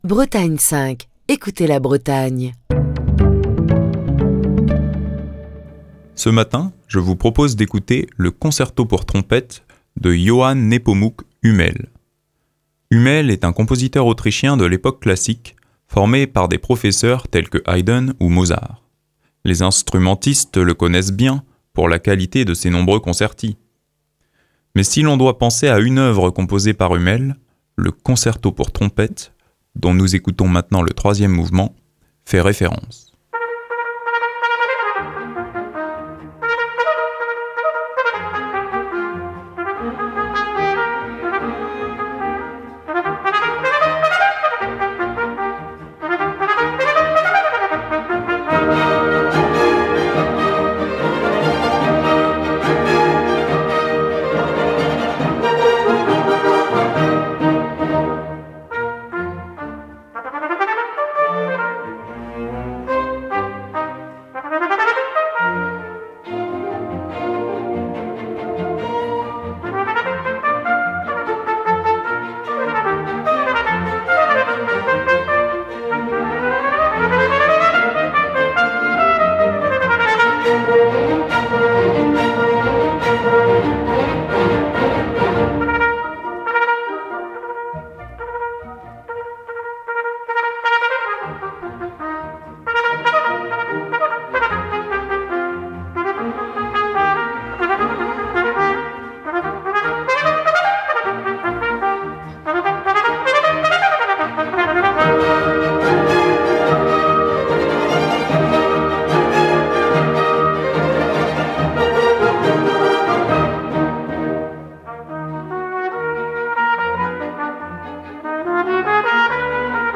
Le Concerto pour trompette et orchestre en mi bémol majeur de Johann Nepomuk Hummel est au programme du Classique du matin ce mardi.